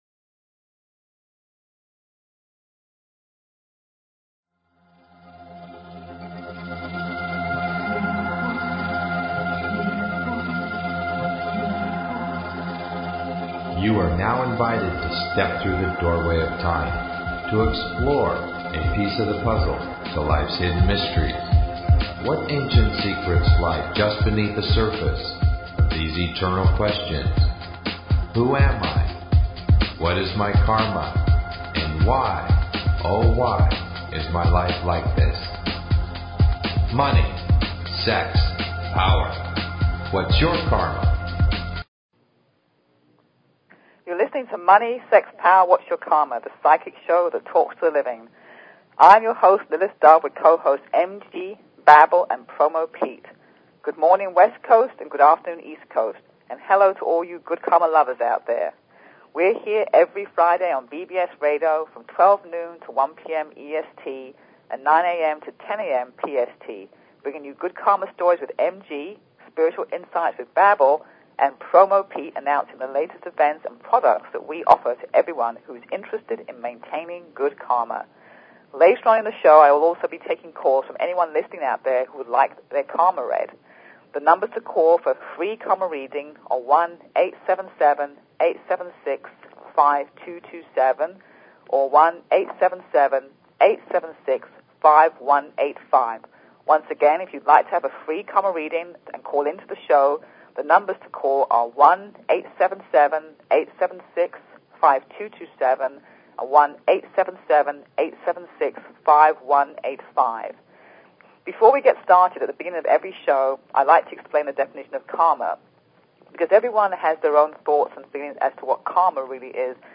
Talk Show Episode, Audio Podcast, Money_Sex_Power_Whats_Your_Karma and Courtesy of BBS Radio on , show guests , about , categorized as
LIVE call-in Karma Readings